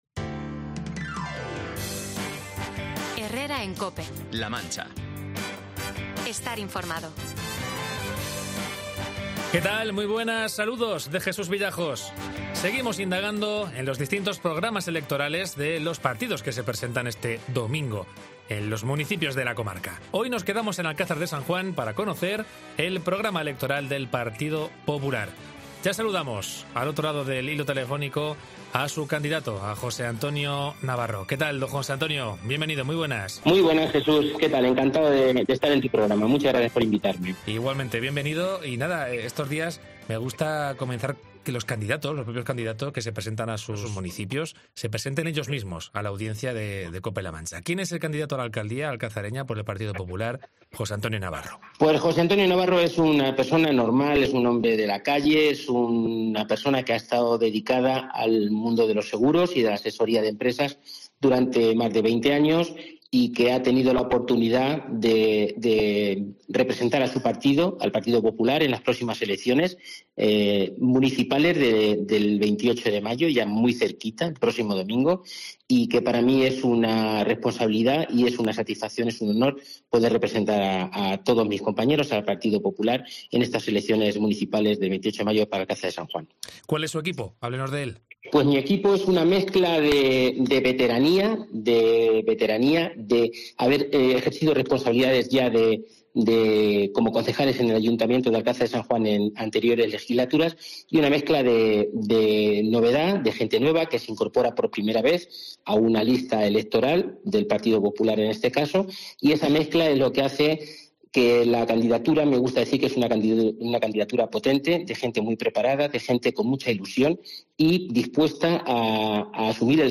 Cope La Mancha Entrevista
En los últimos días, de campaña los principales candidatos de los distintos partidos de la comarca pasan por los micrófonos de Cope La Mancha para explicar su programa electoral.